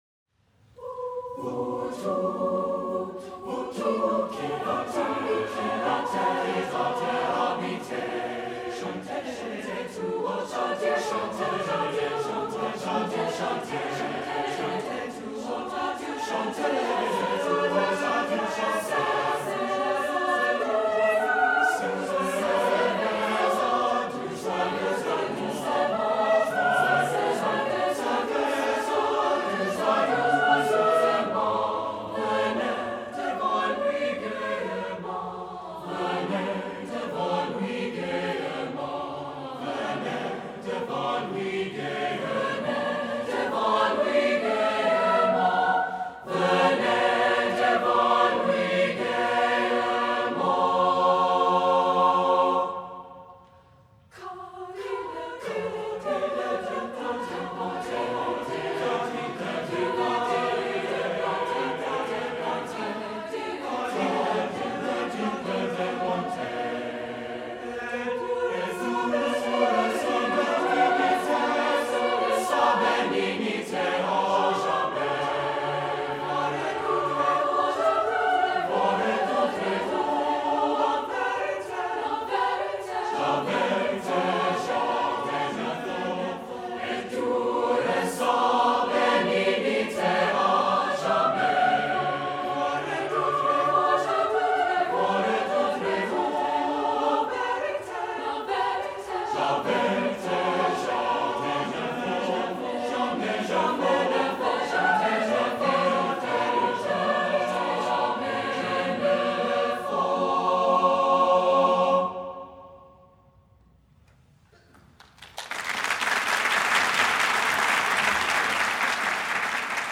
Voicing: SAATB